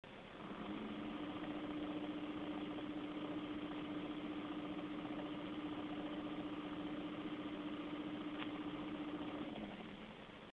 frostytech acoustic sampling chamber - low fan speed
standard waveform view of a 10 second recording. click on the headphones icon to listen to an mp3 recording of this heatsink in operation. the fan is running at its lowest speed in this test.
ASUS Starice low 34.4 dB
The Asus Star Ice heatsink is very quiet at its lowest fan speed, but airflow suffers greatly for that alluring 34.4dBA sound level.